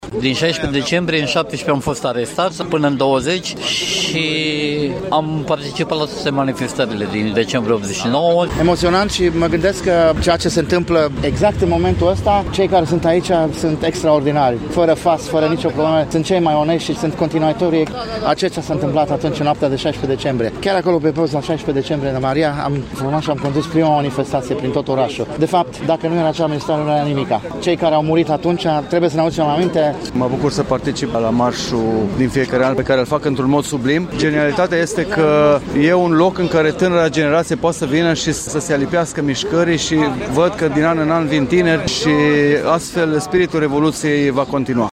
Peste 400 de persoane au participat la Marşul Eroilor de la Timişoara care s-a desfășurat în această seară.
01-vox-mars-21.mp3